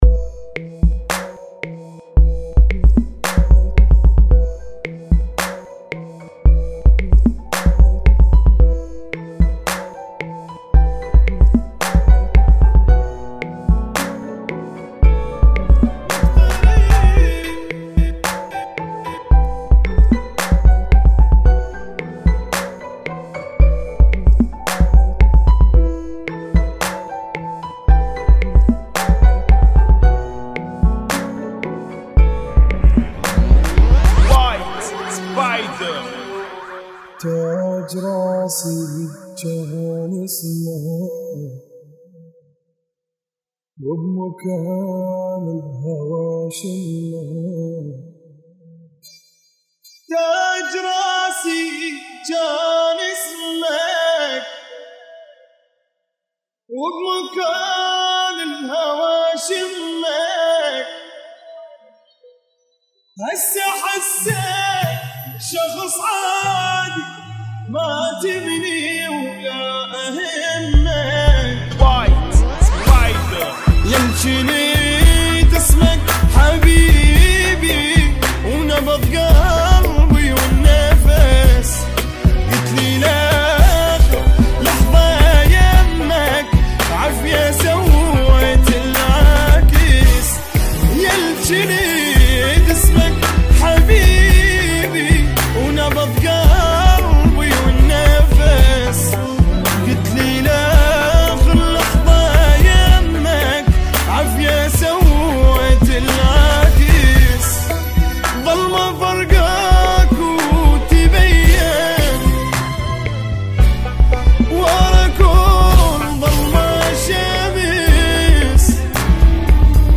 Funky [ 56 Bpm ]